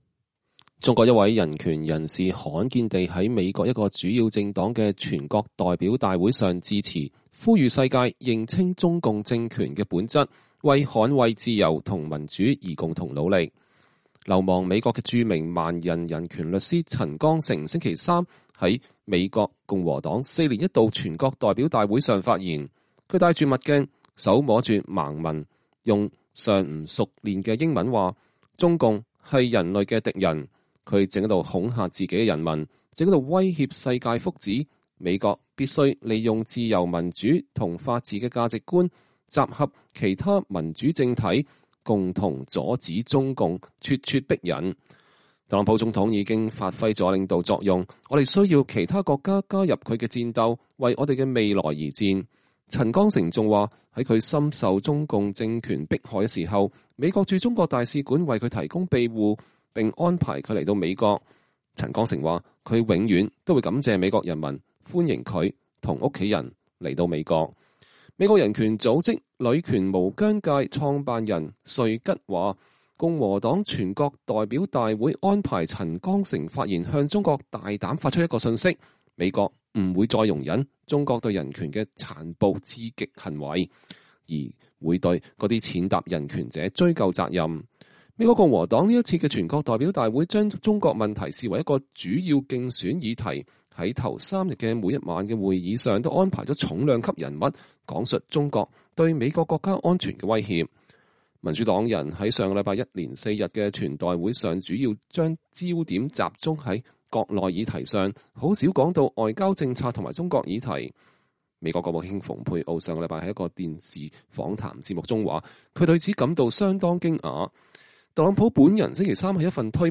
流亡美國的著名盲人人權律師陳光誠星期三（2020年8月26日）在美國共和黨四年一度全國代表大會上發言（美國共和黨全代會視頻截圖）
他戴著墨鏡，手摸著盲文，用尚不熟練的英語說：“中共是人類的敵人，它正在恐嚇自己的人民，正在威脅世界的福祉。美國必須利用自由、民主和法治的價值觀，集合其他民主政體，共同阻止中共的咄咄逼人。特朗普總統已經發揮了領導作用，我們需要其他國家加入他的戰鬥，為我們的未來而戰。”